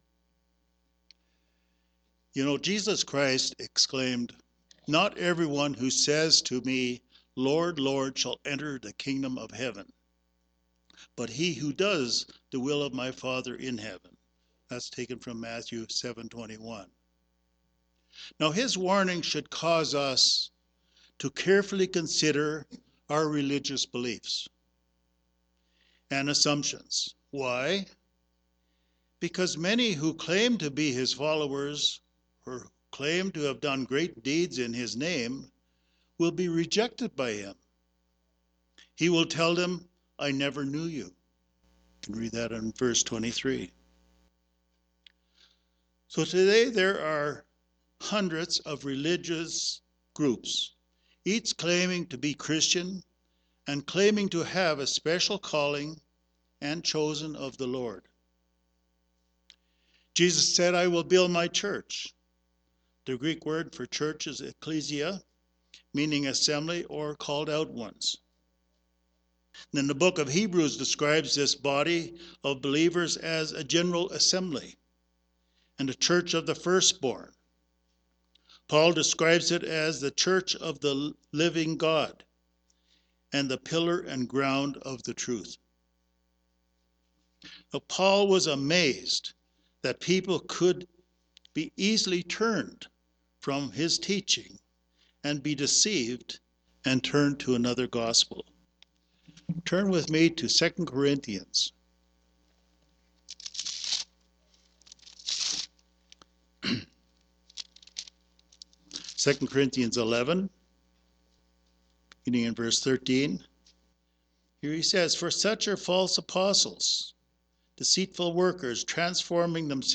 Given in Denver, CO